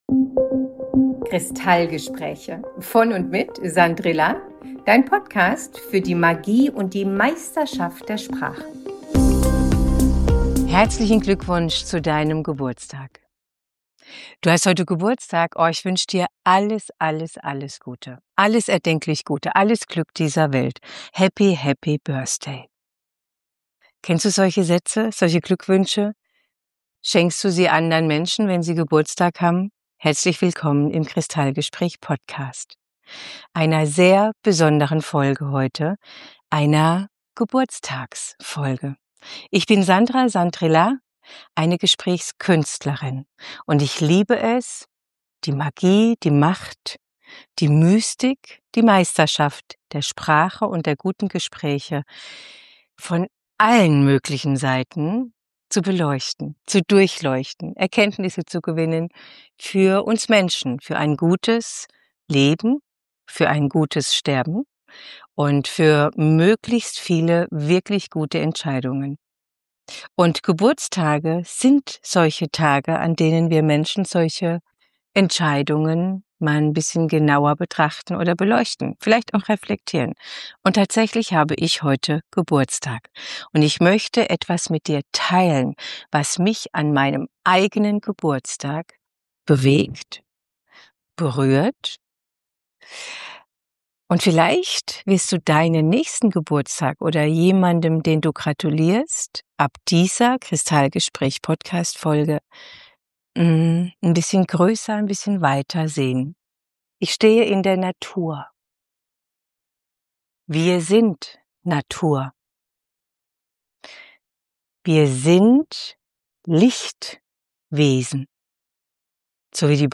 Denn ich habe heute am Tag der Aufnahme Geburtstag und feiere diesen Tag an einem meiner Kraftorte: mitten in der Natur auf Nordzypern. Zwischen Olivenhainen und dem weiten Himmel stelle ich mir – und dir natürlich – die wesentlichen Fragen über unsere ‚Menschenzeit‘.